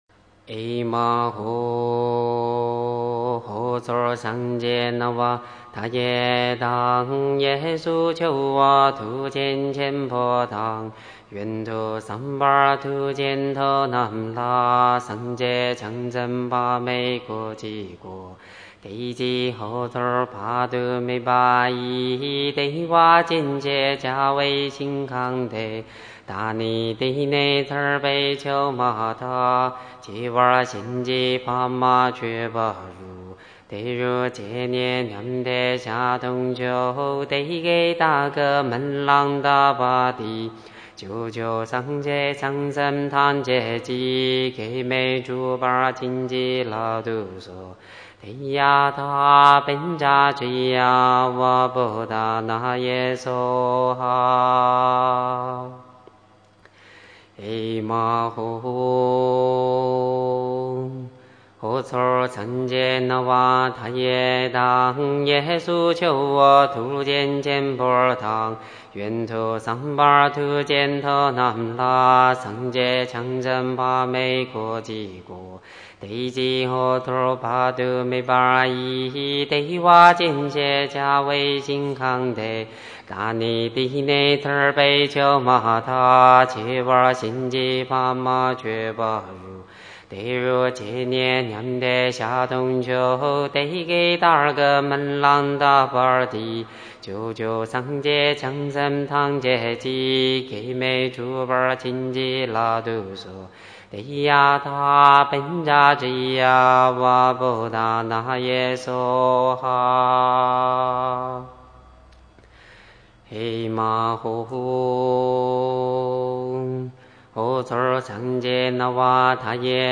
佛曲音樂 > 讚偈/偈頌/祈請文 > 極樂淨土祈請文(藏傳)